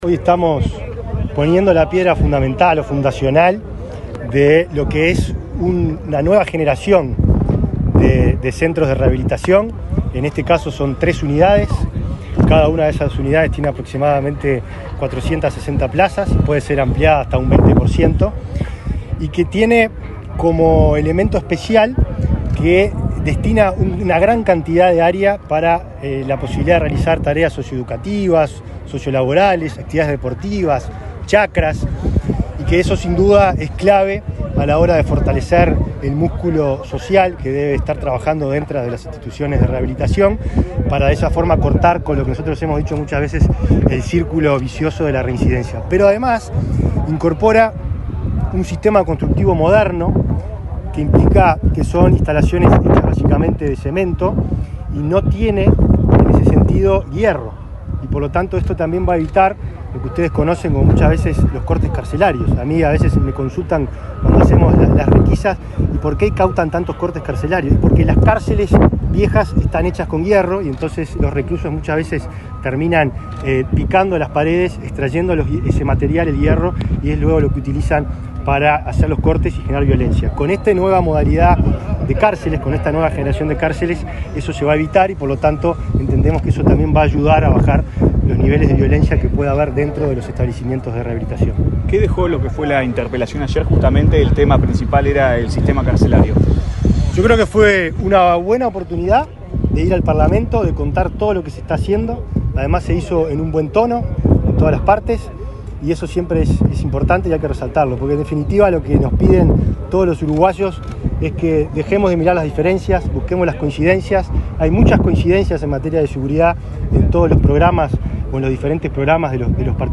Declaraciones del ministro del Interior, Nicolás Martinelli
Luego, dialogó con la prensa.